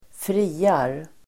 Uttal: [²fr'i:ar]